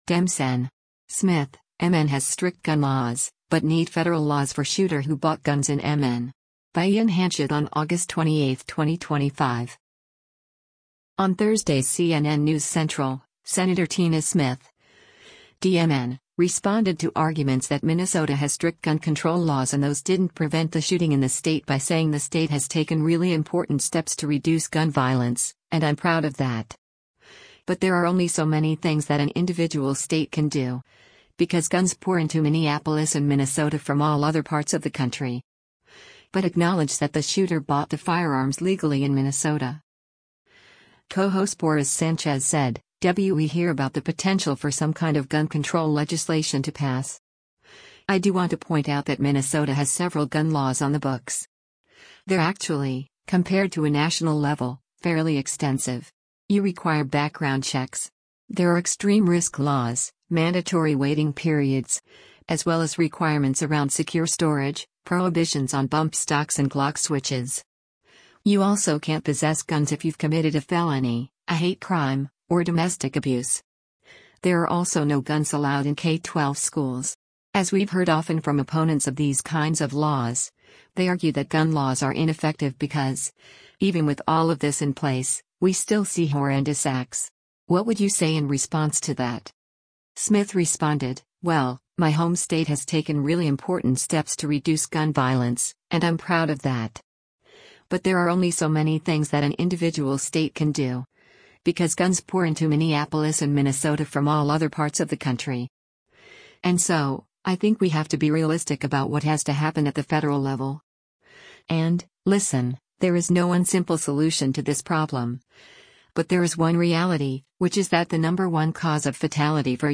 On Thursday’s “CNN News Central,” Sen. Tina Smith (D-MN) responded to arguments that Minnesota has strict gun control laws and those didn’t prevent the shooting in the state by saying the state “has taken really important steps to reduce gun violence, and I’m proud of that.